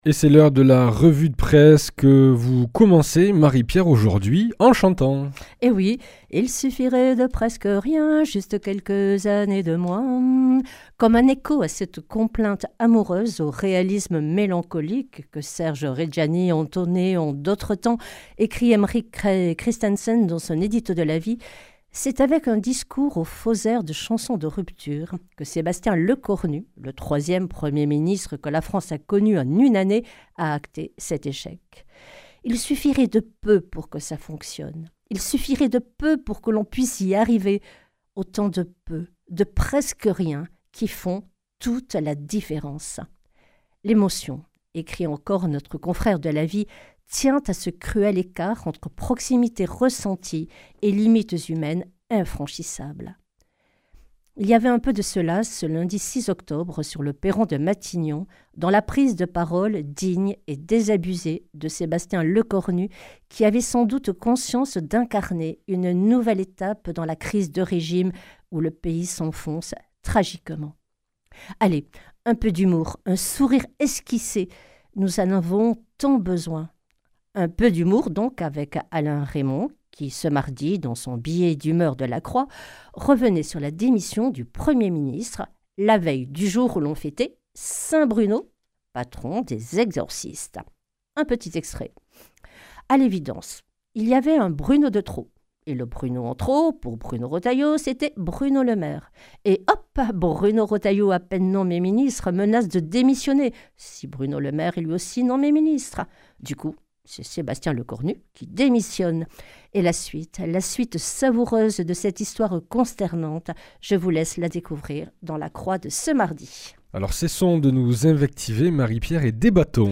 La revue de presse chrétienne du 10 oct.